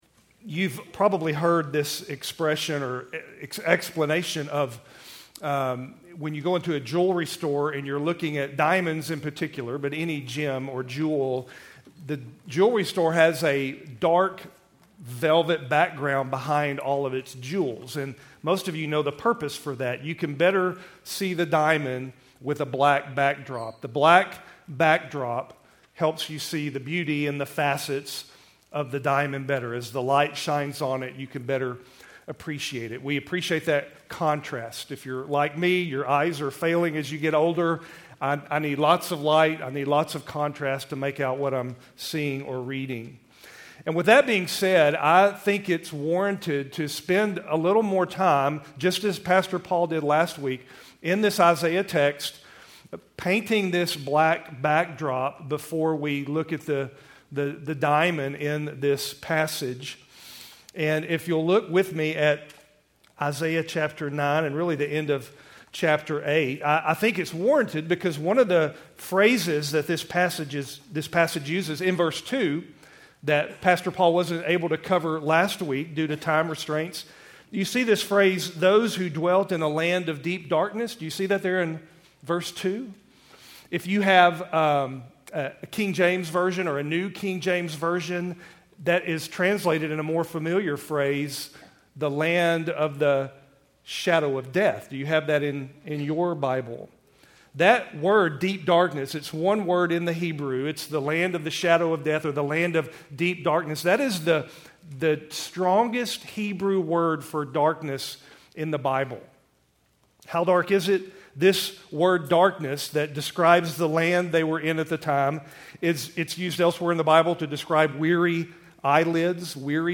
Keltys Worship Service, December 22, 2024